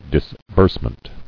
[dis·burse·ment]